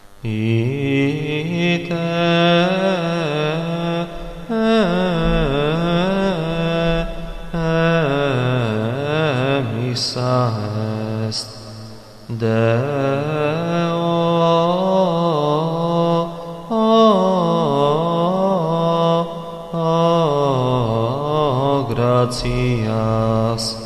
Strumenti: Sussidii liturgici: La Santa Messa cantata